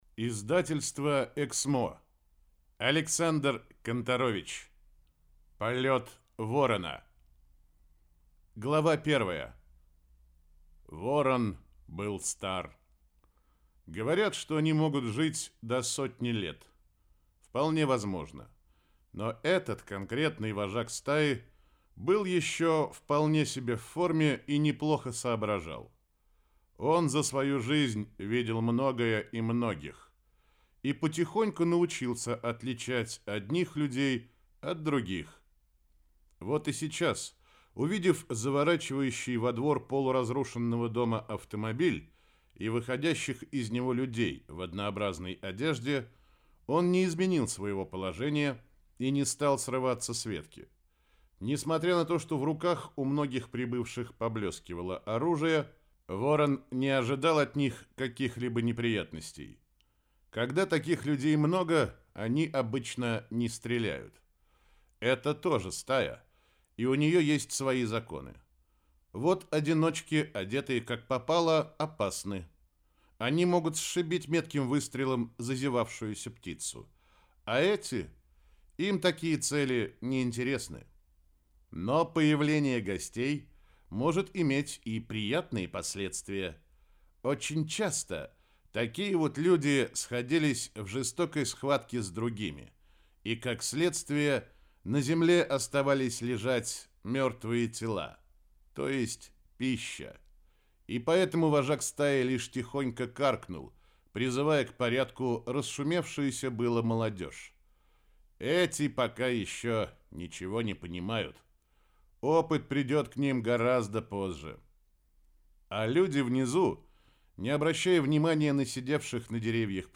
Аудиокнига Полет ворона - купить, скачать и слушать онлайн | КнигоПоиск